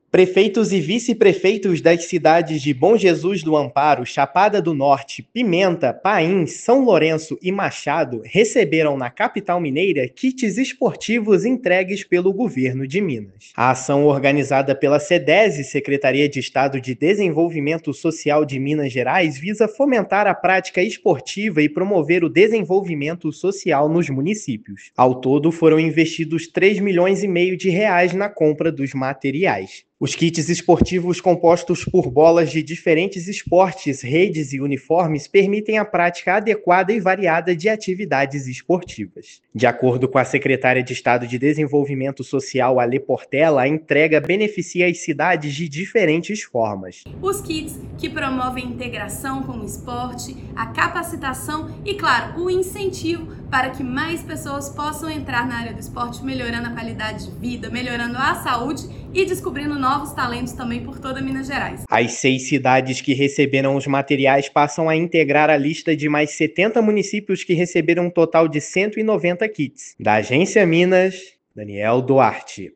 [RÁDIO] Governo de Minas distribui kits esportivos para municípios e impulsiona desenvolvimento social
Ao todo, mais de R$ 3,5 milhões já foram investidos na compra e entrega do material para cidades de todo o estado. Ouça a matéria de rádio: